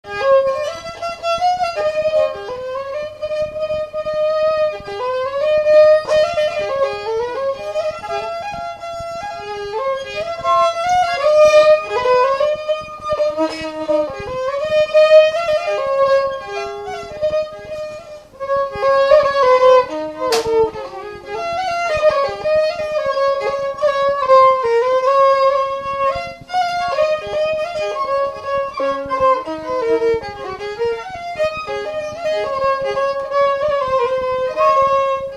Mazurka
Instrumental
danse : mazurka
Pièce musicale inédite